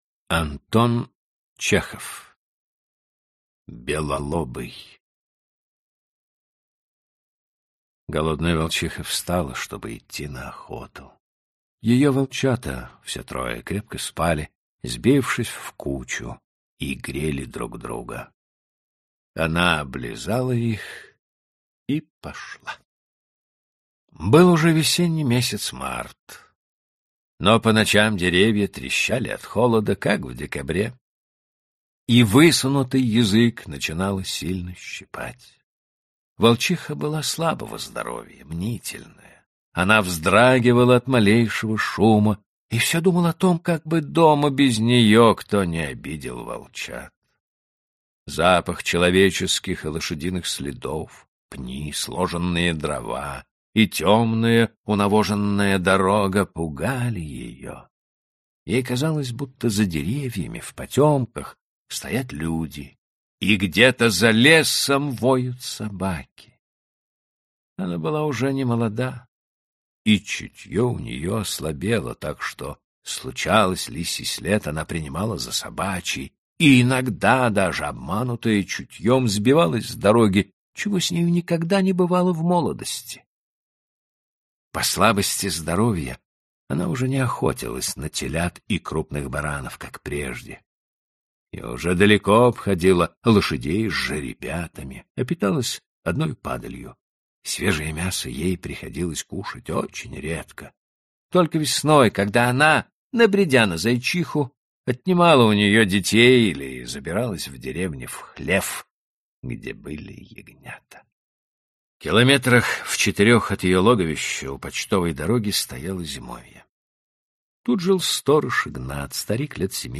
Аудиокнига Рассказы классиков о животных. Волки | Библиотека аудиокниг